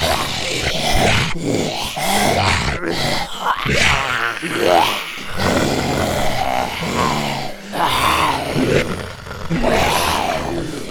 Zombie_04.wav